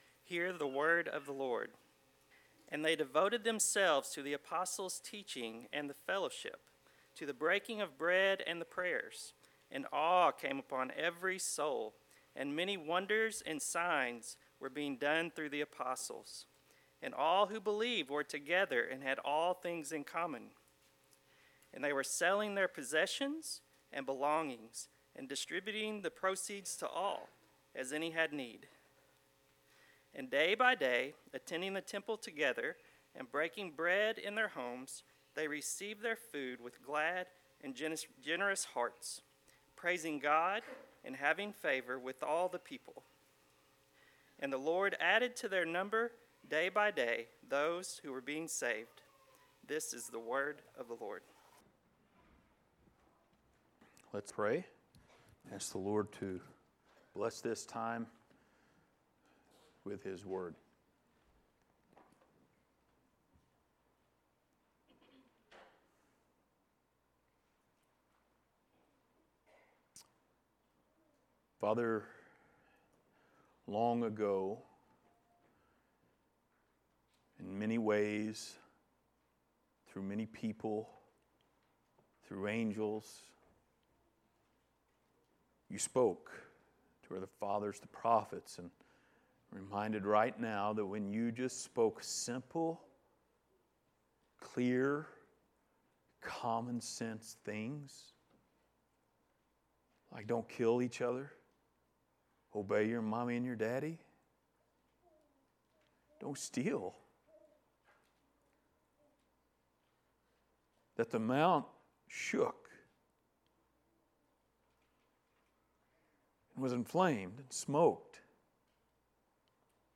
Passage: Acts 2:42-47 Service Type: Sunday Morning Related Topics